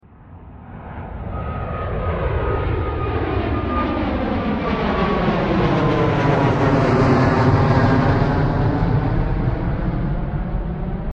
aeroplane.mp3